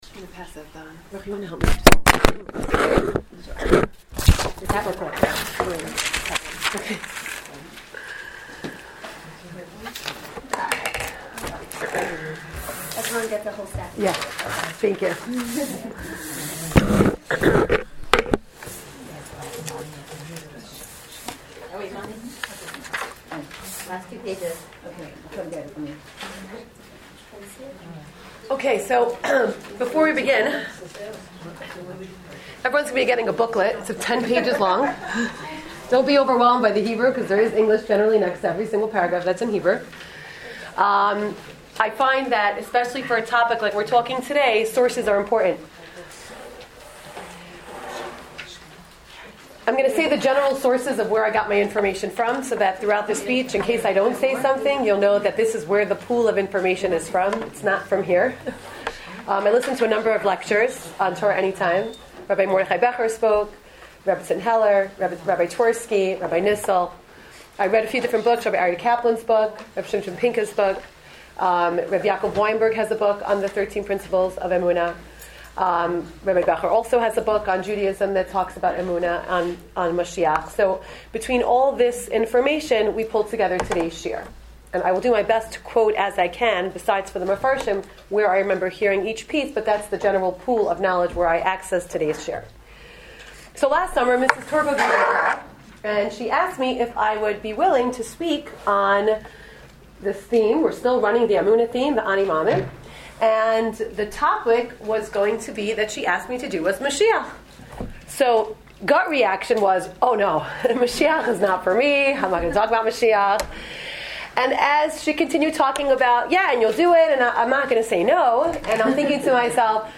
GUEST LECTURER
Rosh Chodesh Lecture Series